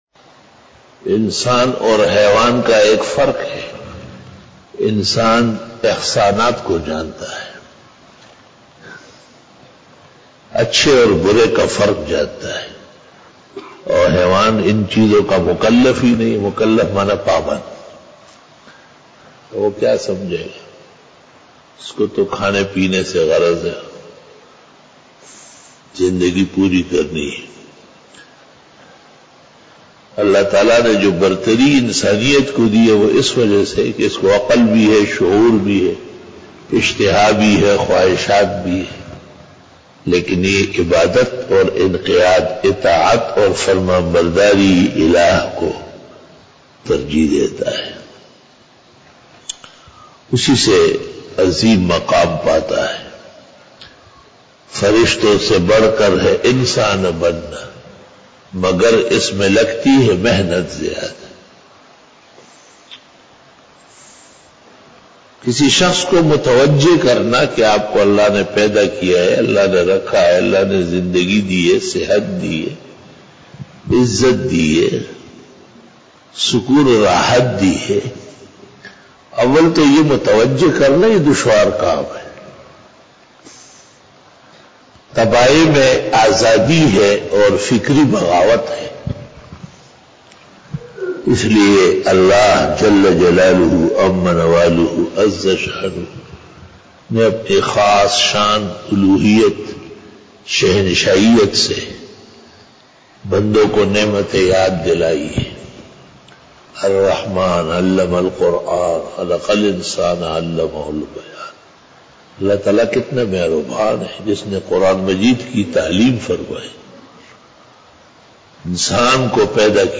After Fajar Byan
بیان بعد نماز فجر بروز جمعرات